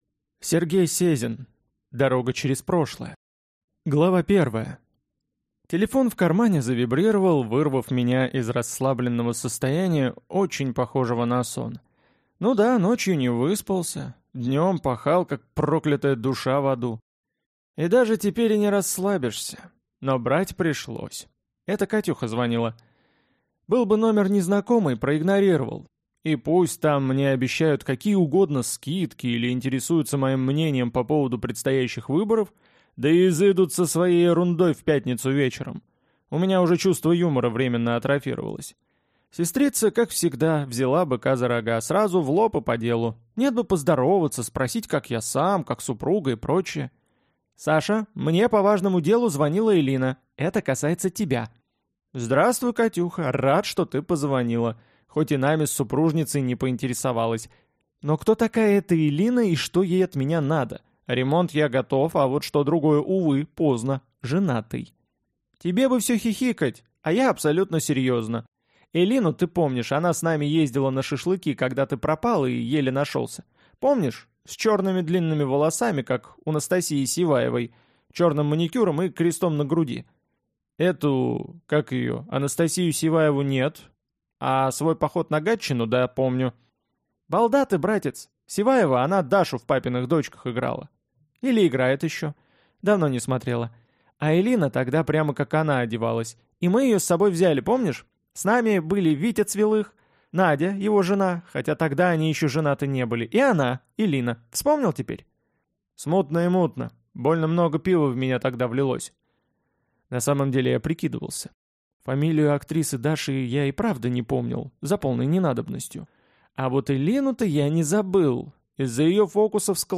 Аудиокнига Дорога через прошлое | Библиотека аудиокниг